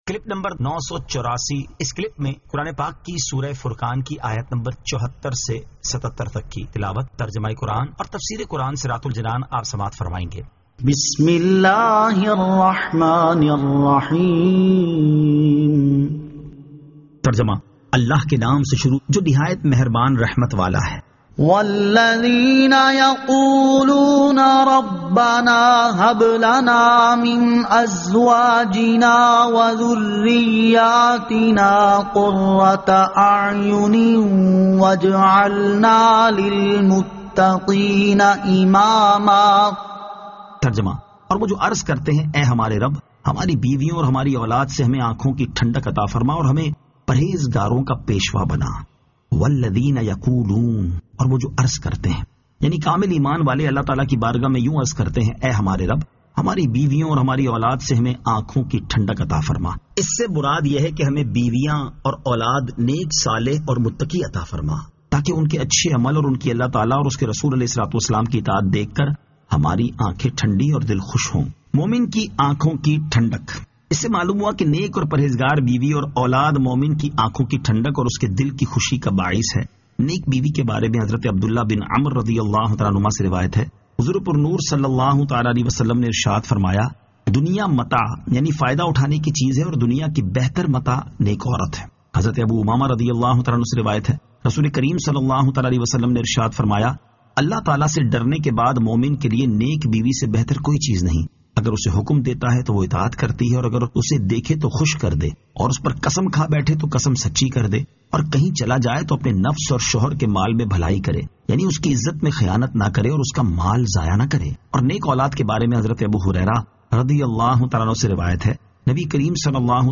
Surah Al-Furqan 74 To 74 Tilawat , Tarjama , Tafseer